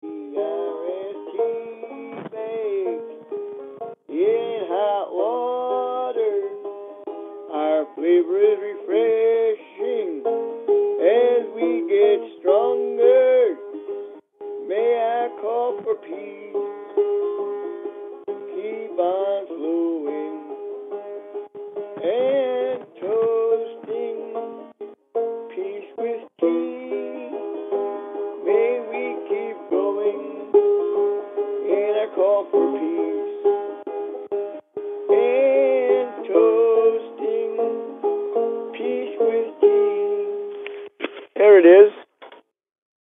He plays piano, banjo, guitar, autoharp and harmonica.